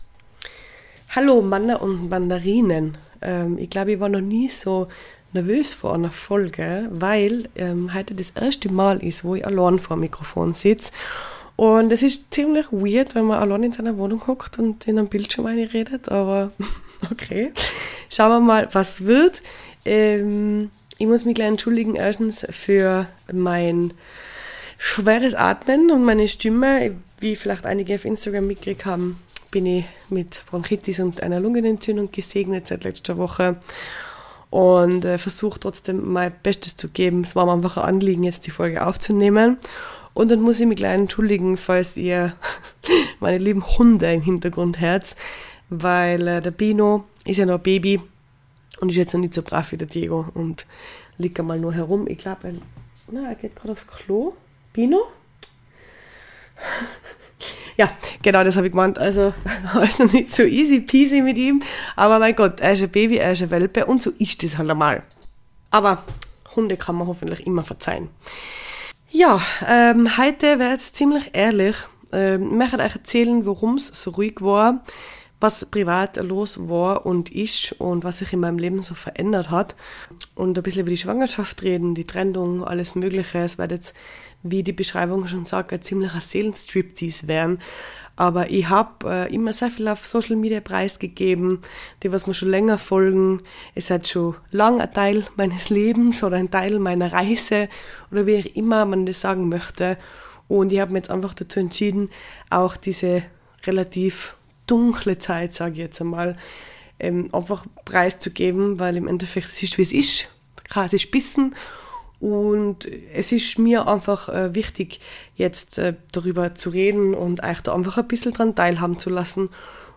Beschreibung vor 1 Jahr danke! ihr lieben für die geduld, und dass ihr wieder reinhört! in dieser folge, in der ich erstmals allein bin, erzähl ich euch was so los war in den letzten wochen, monaten und warum der podcast ein wenig vernachlässigt wurde. eine sehr private folge, die mir aber sehr am herzen liegt. schöne weihnachtszeit ihr lieben!